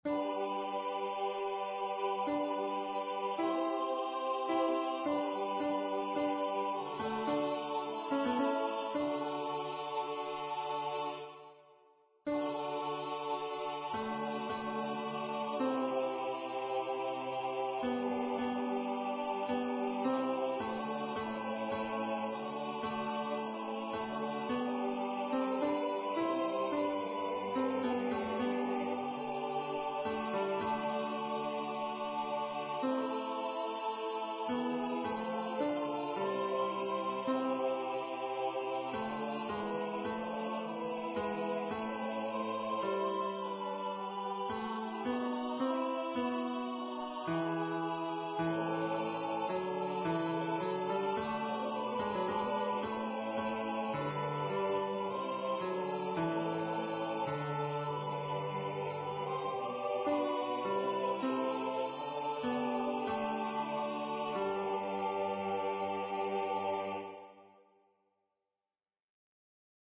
Tenor practice part is piano, other parts subdued voice